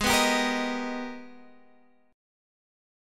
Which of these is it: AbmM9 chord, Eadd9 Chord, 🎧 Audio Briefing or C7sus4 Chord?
AbmM9 chord